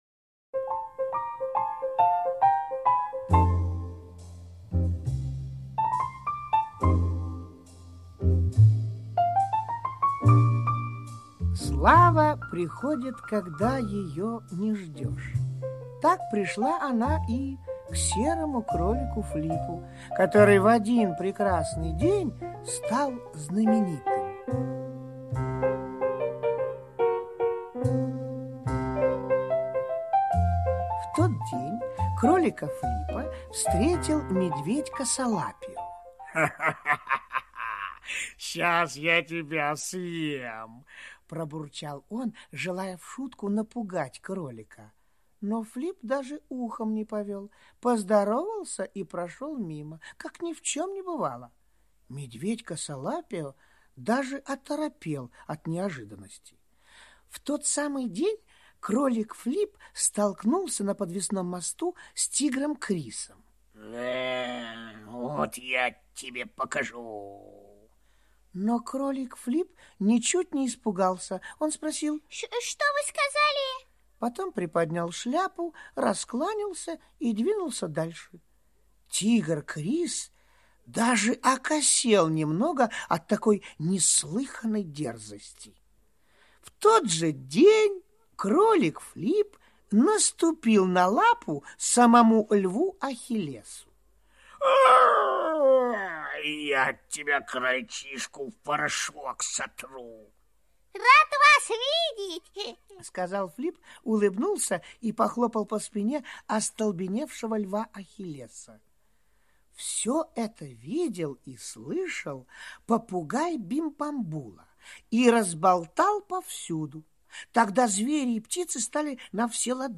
Слушайте Кролик, который ничего не боялся - аудиосказка Пляцковского М.С. Сказка про Кролика, который не испугался угроз Медведя,Тигра и Льва.